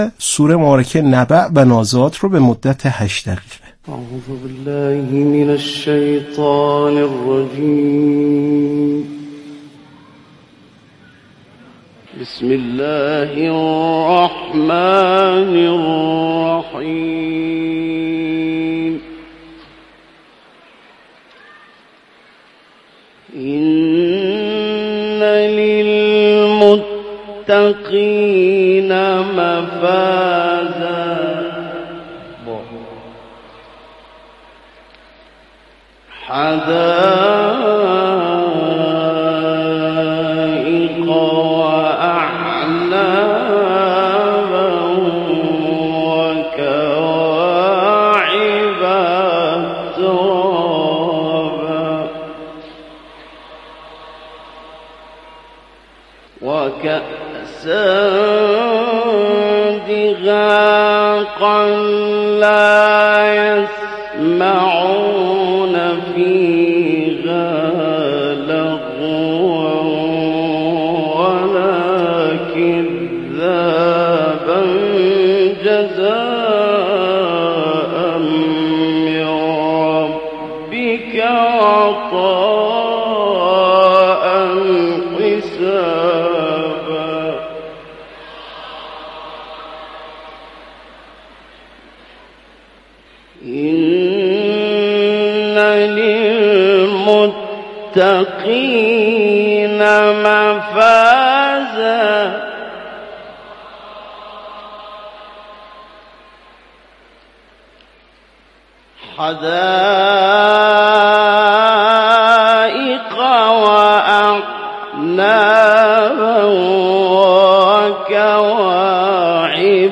یادآور می‌شود، این تحلیل در برنامه «اکسیر» از شبکه رادیویی قرآن پخش شد.